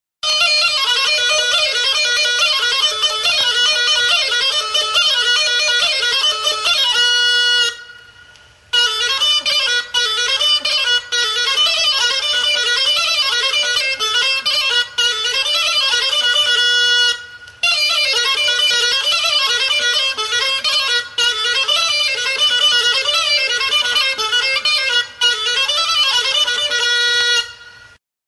Aerofonoak -> Mihiak -> Bakun (klarinetea)
SOLO DE XEREMIA.